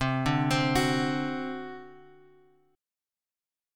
C Suspended 2nd Flat 5th